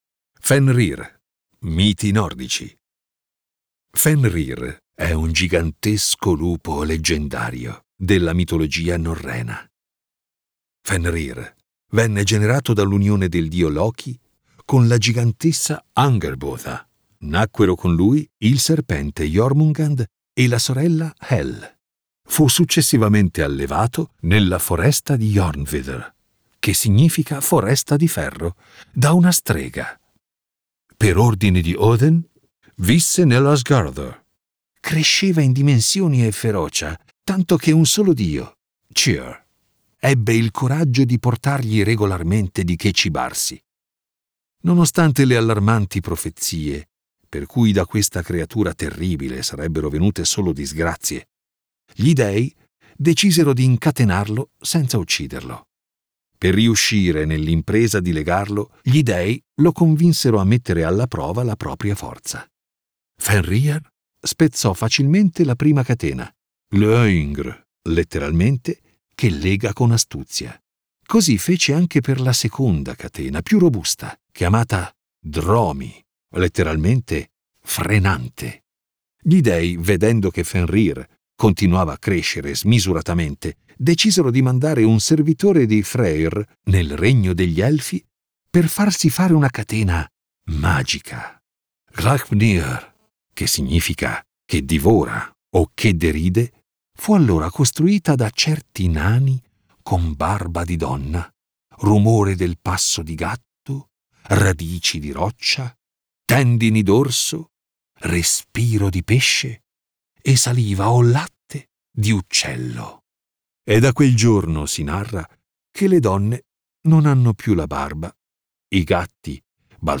Fiabe e leggende Tutto Mondo